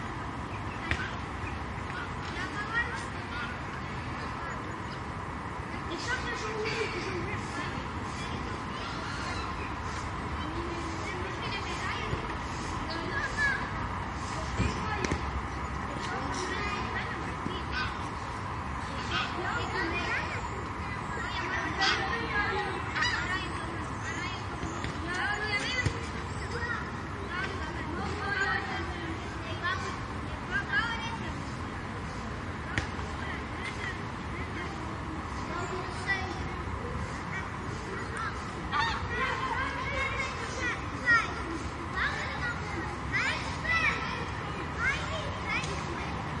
城市夜晚环境声景
描述：城市夜晚环境声景。
标签： 环境 夜晚 安静 街道 城市声音
声道单声道